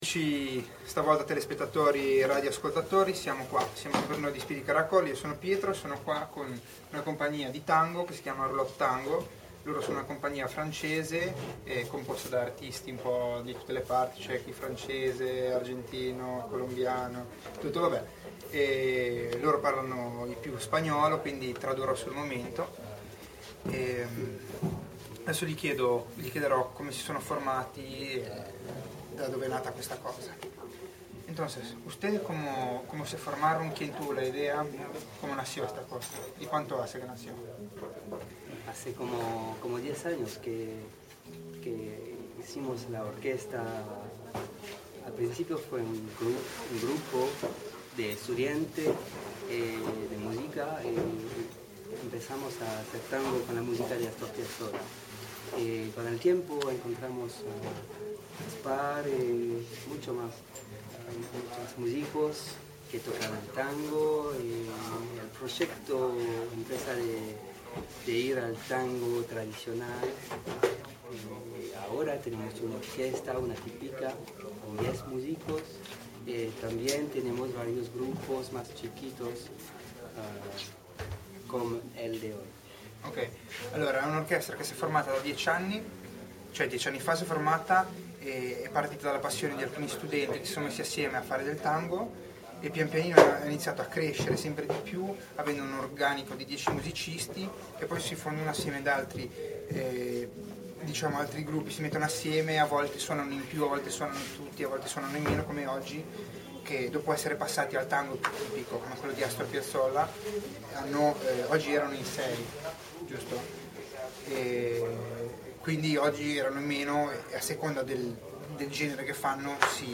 Intervista a Roulotte Tango
play_circle_filled Intervista a Roulotte Tango Radioweb C.A.G. di Sestri Levante Artista di Strada intervista del 08/06/2012 Compagnia Franco-Argentina specializzata in tango argentino. L'abbiamo intervistata in occasione del Festival Andersen